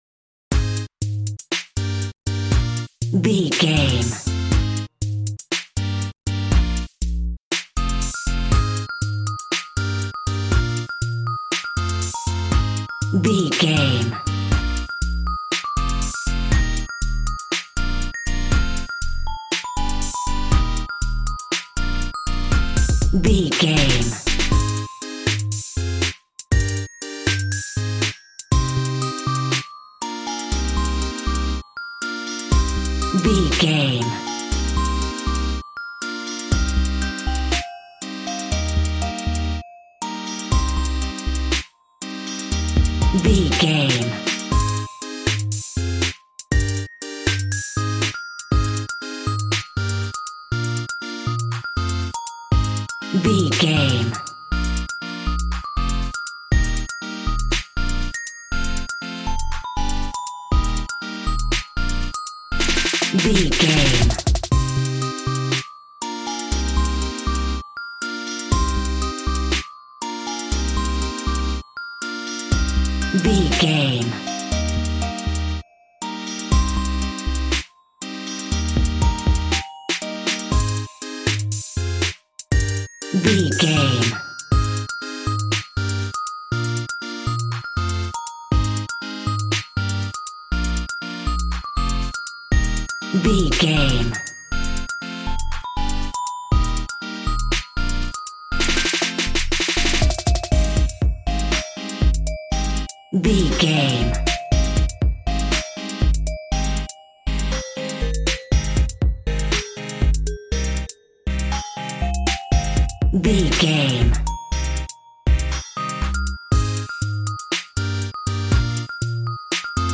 A groovy and explosive piece of cool street hip hop.
Aeolian/Minor
E♭
Fast
groovy
synthesiser
drums
cool
piano